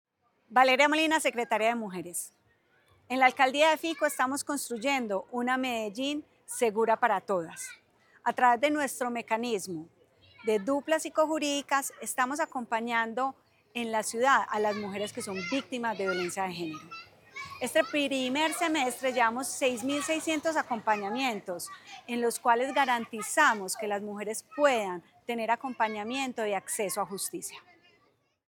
Declaraciones secretaria de las Mujeres, Valeria Molina
Declaraciones-secretaria-de-las-Mujeres-Valeria-Molina.mp3